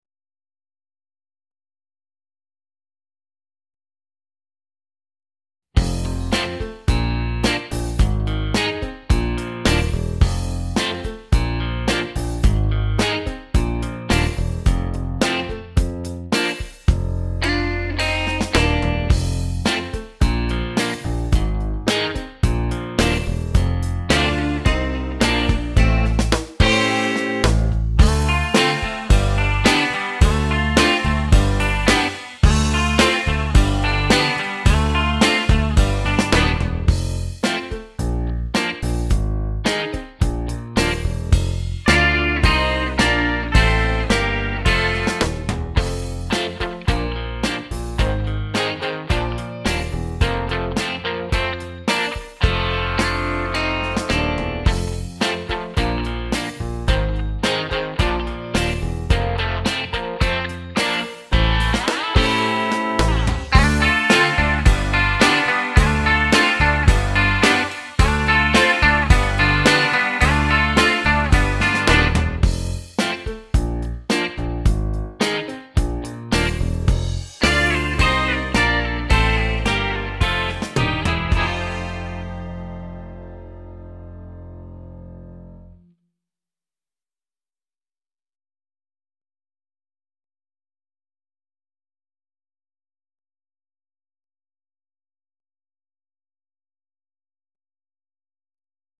23 Mr Kool (Backing Track)